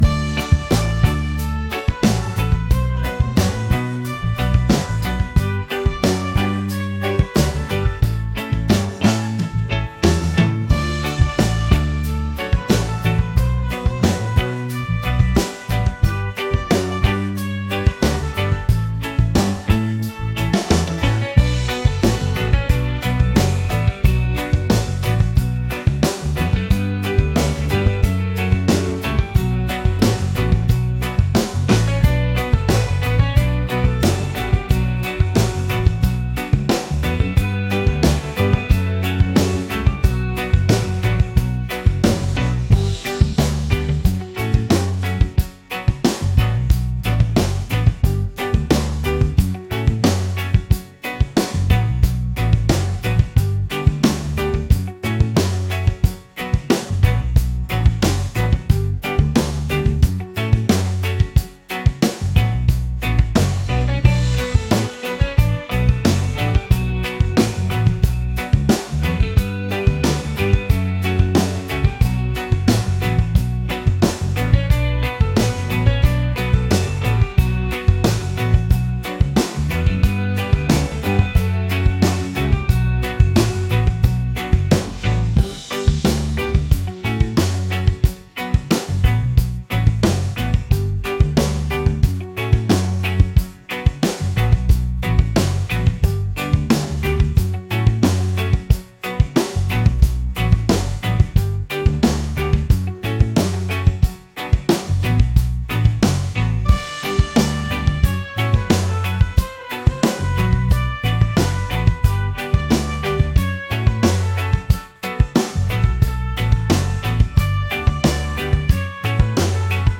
upbeat | reggae | fusion